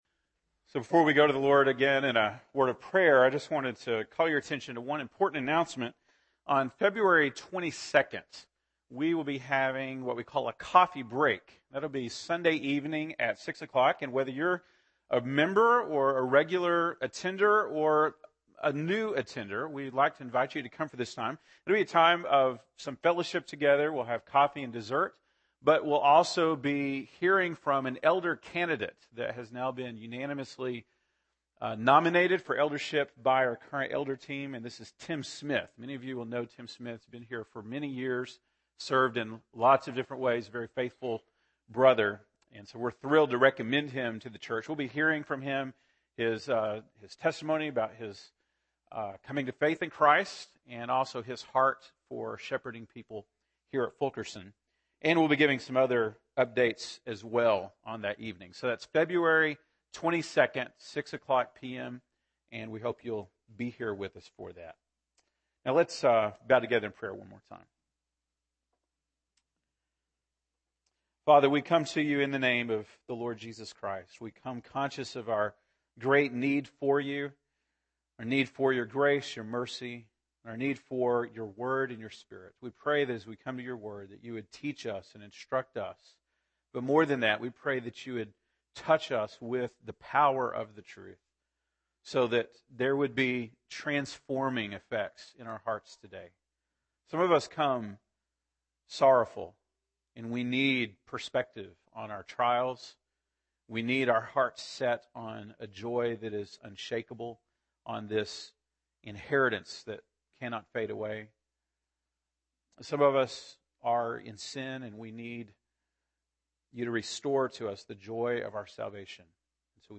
February 8, 2015 (Sunday Morning)